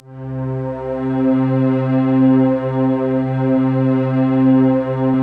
Index of /90_sSampleCDs/Optical Media International - Sonic Images Library/SI1_OrchestChoir/SI1_Dark&moody